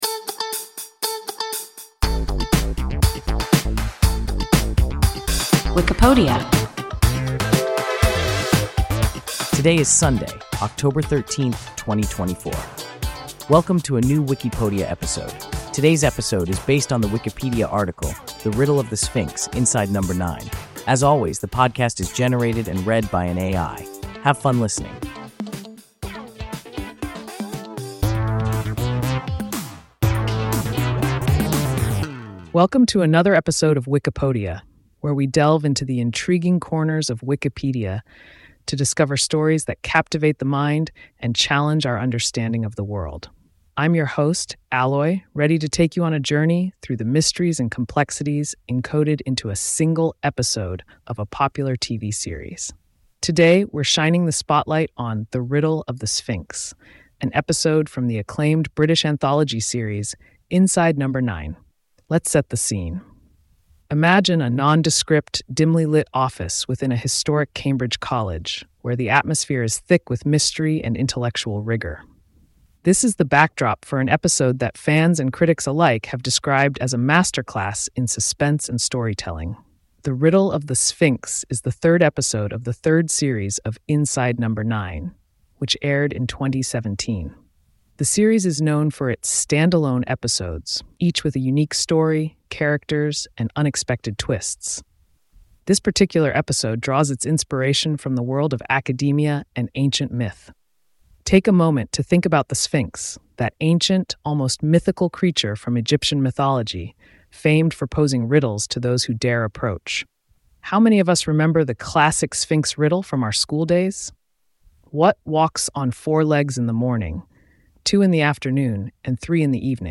The Riddle of the Sphinx (Inside No. 9) – WIKIPODIA – ein KI Podcast